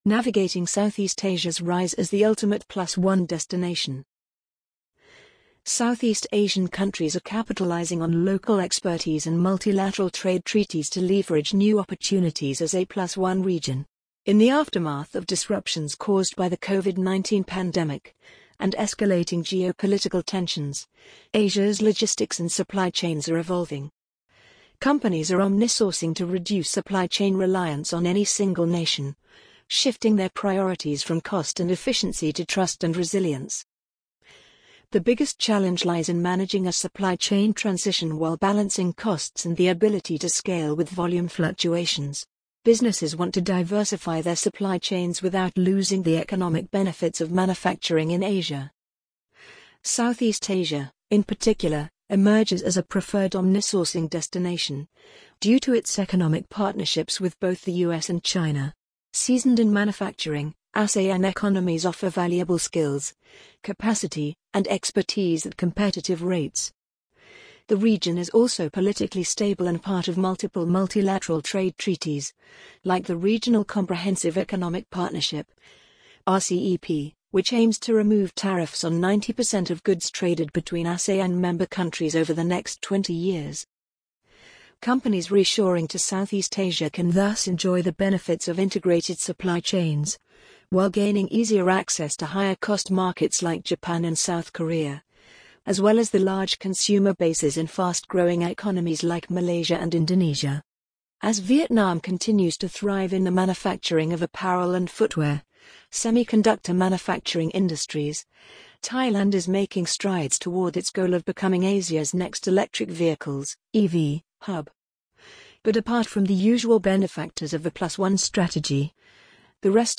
amazon_polly_47072.mp3